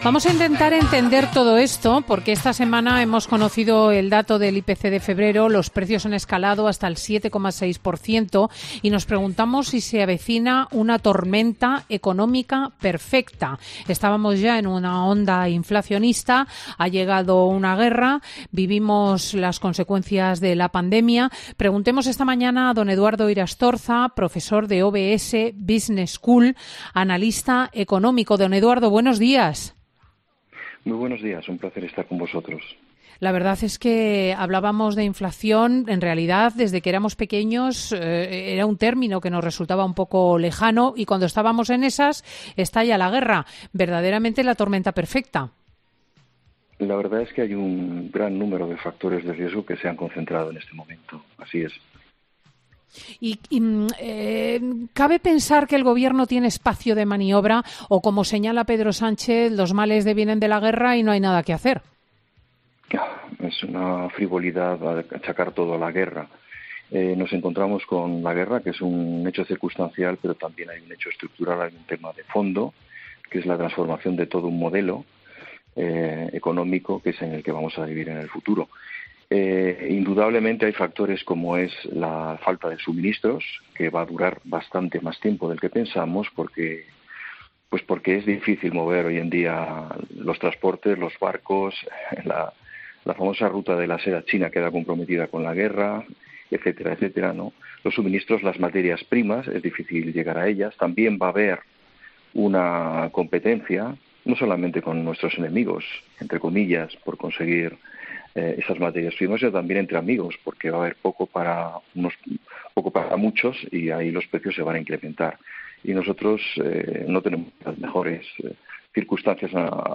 analista económico